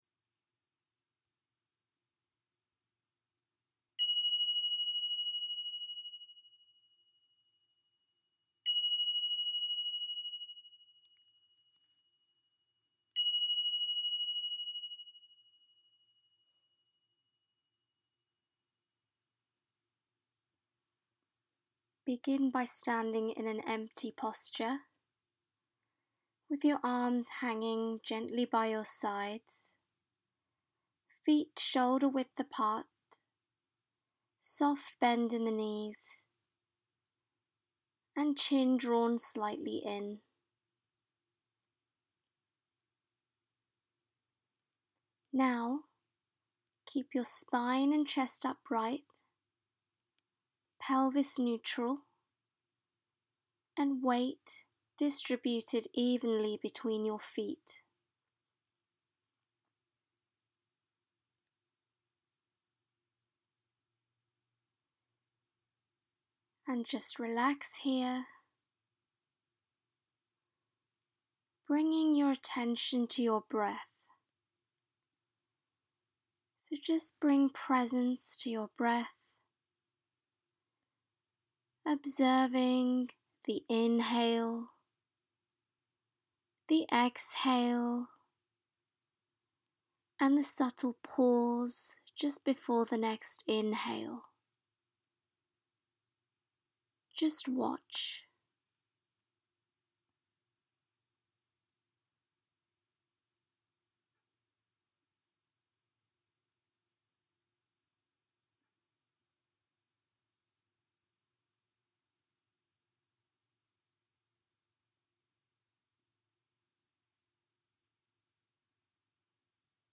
Guided Standing Meditation Practice